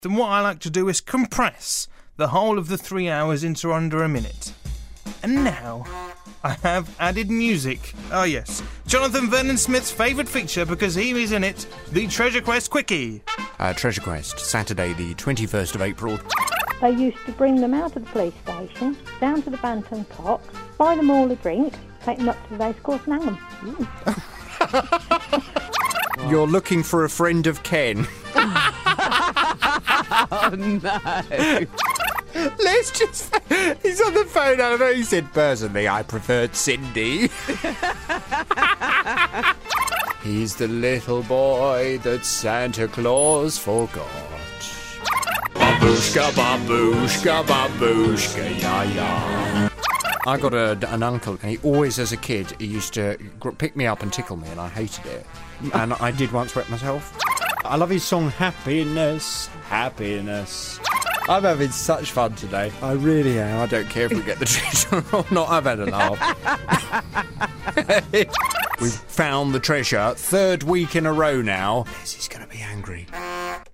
3 hours of Treasure Quest magic from the 21st of April from BBC Radio Northampton in under a minute.